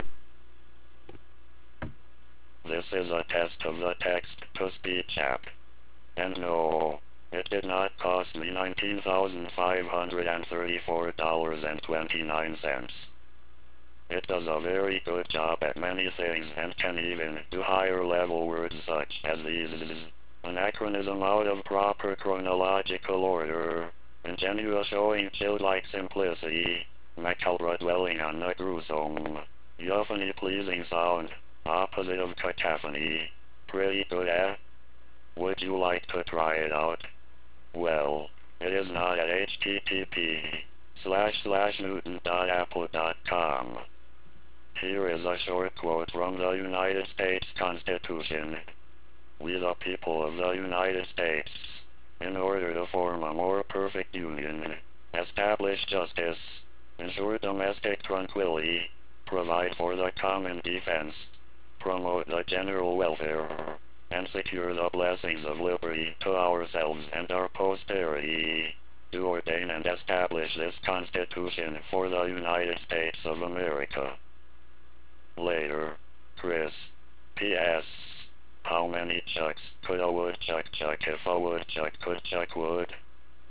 I have recorded the two programs and encoded it in real audio 2.0 for 28.8 modems!
MacTalk has many different voices and controls, so here is the normal reading the same as TalkToMe: